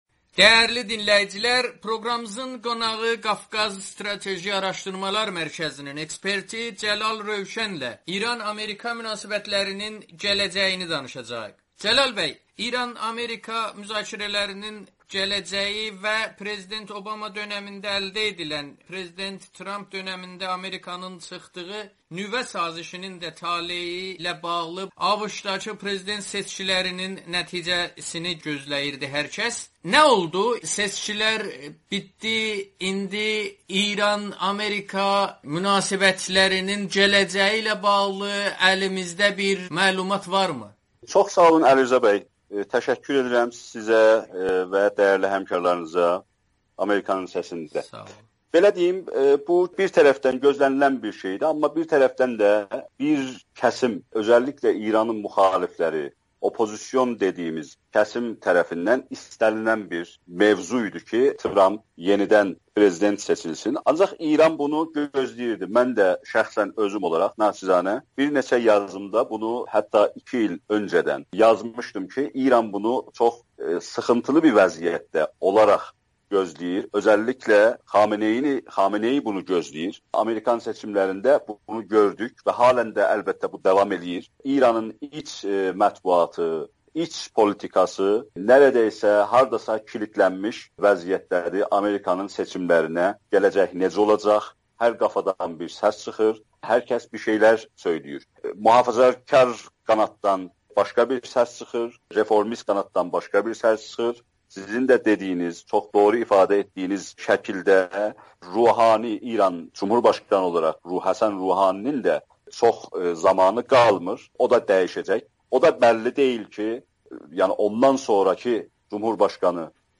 Amerikanın Səsinə danışan siyasi şərhçi